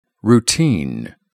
Word Stress Pattern IPA (RP) Received Pronunciation General American
routine /21/ /ˌruː.’tiːn/ routine